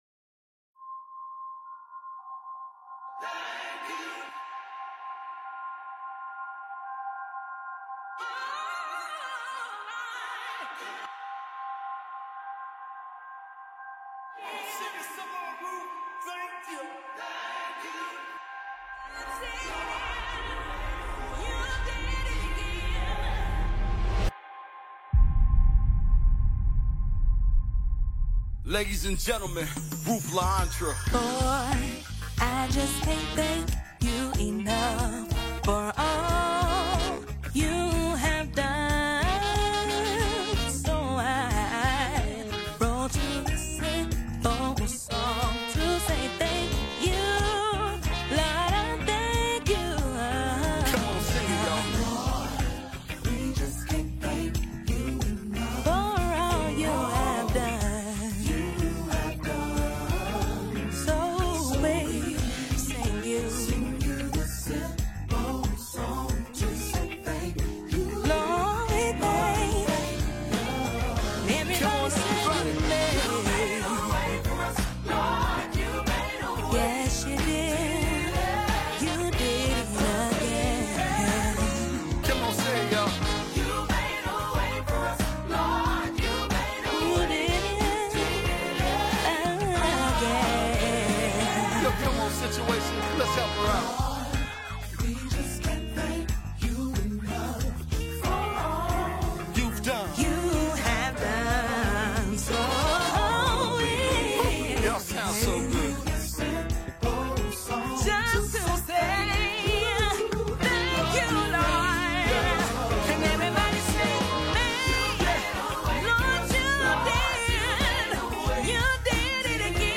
A young gospel music veteran
fun, bouncy, up-tempo track